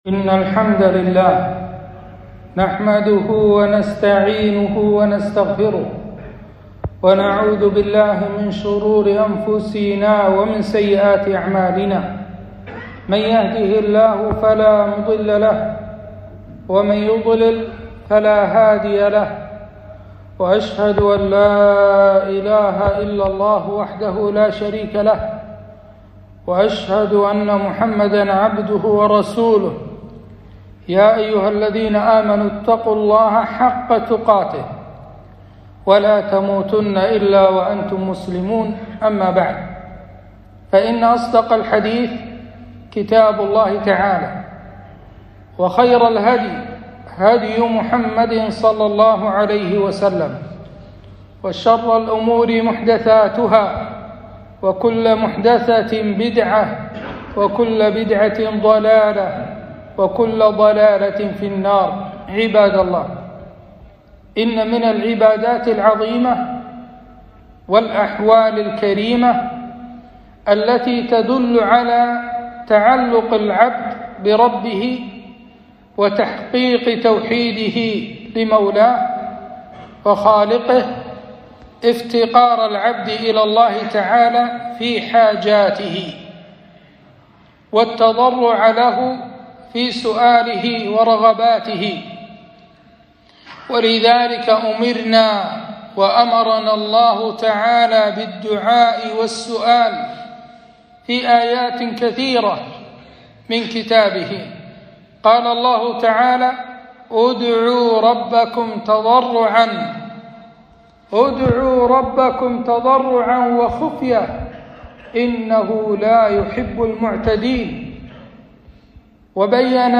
خطبة - فضل الدعاء وأهميته